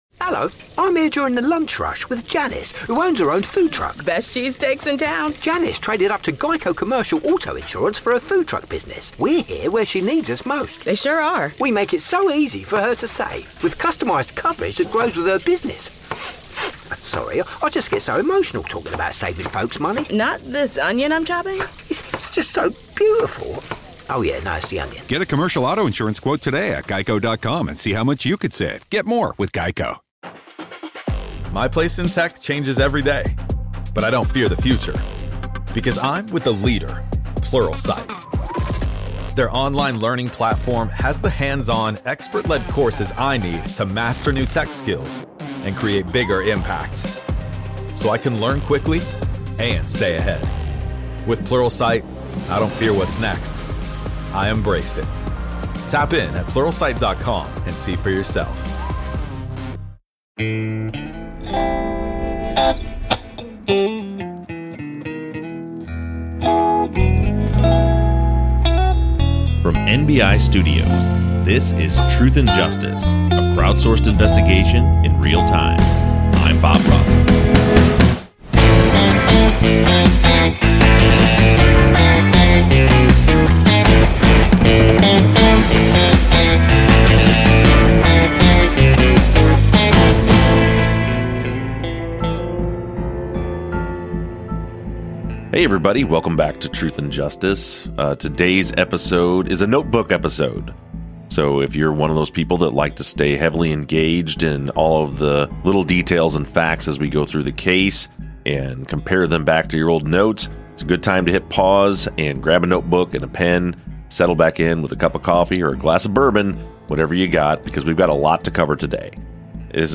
playing us a short police phone call